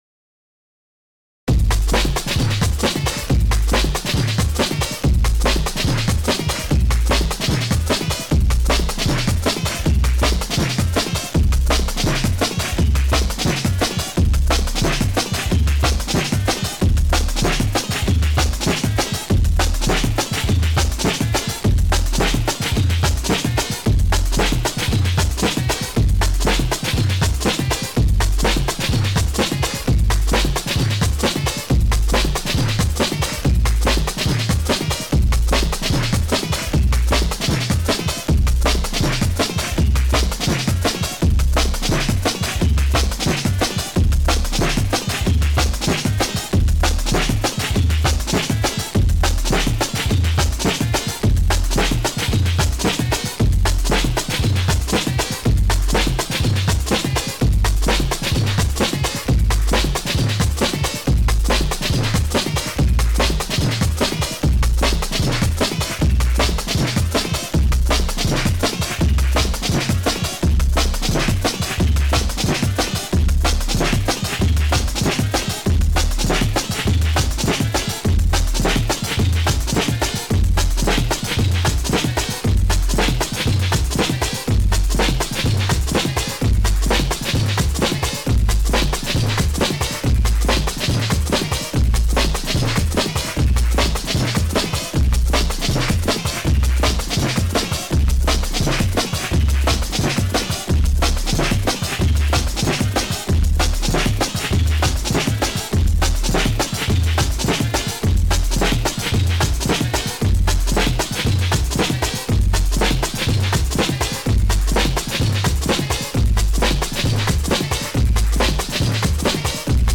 This is an other audio illusion which gives the impression that a sound accelerates eternally.
Here is how it works : we play an accelerating beat, then we gradually mix it with a second beat, twice slower, which accelerates the same way.
Then we gradually add a third beat, four times slower than the first one. When the first beat gets too fast, we fade it out and so on.
Rythme-Risset-acceleration.mp3